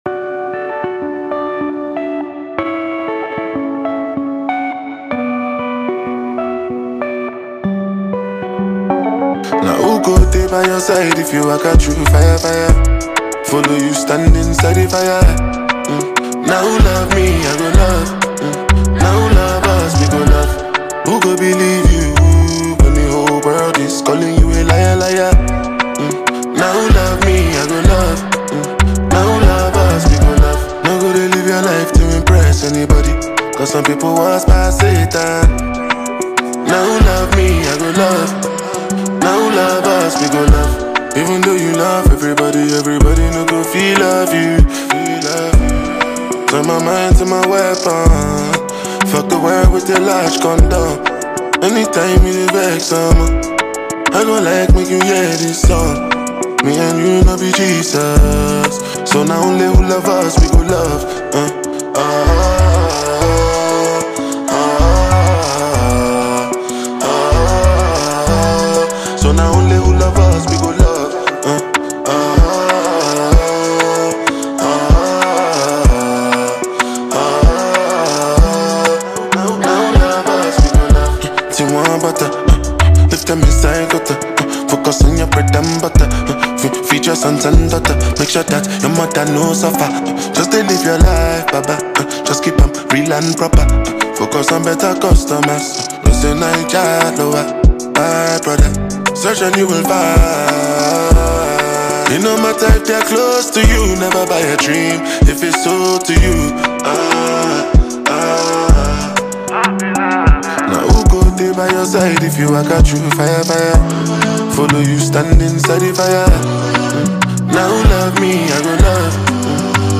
Built around smooth vocals and dreamy synths